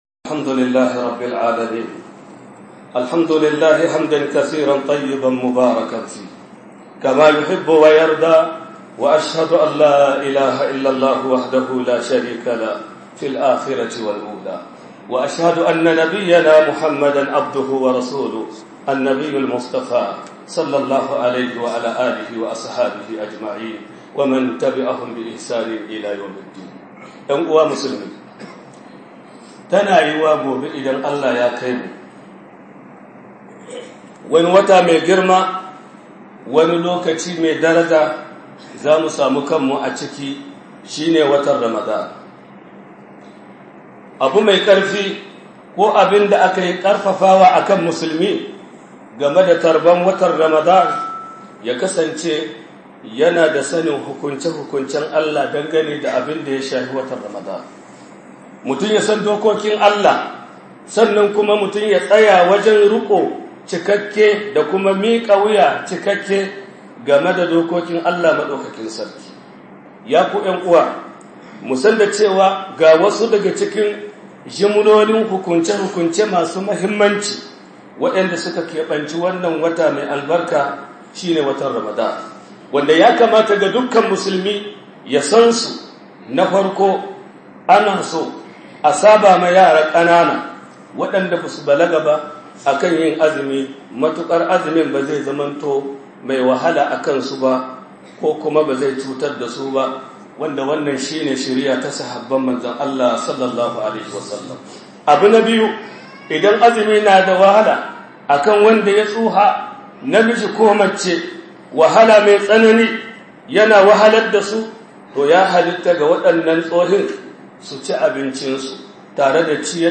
خطبة بهوسا~1